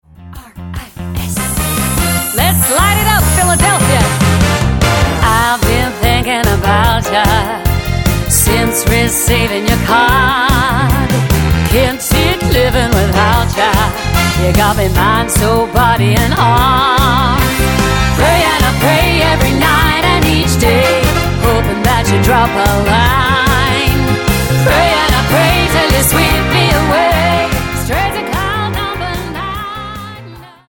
Tonart:Fm Multifile (kein Sofortdownload.
Die besten Playbacks Instrumentals und Karaoke Versionen .